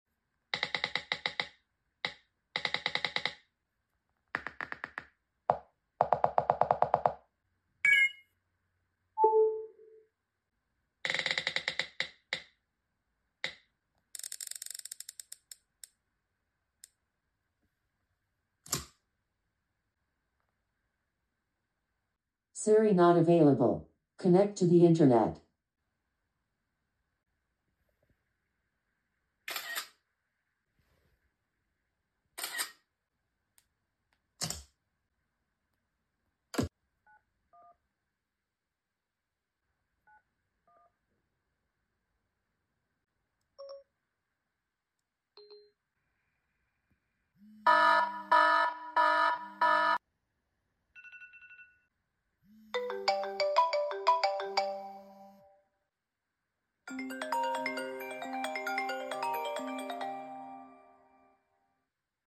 iOS 6 vs. iOS 11 sound effects free download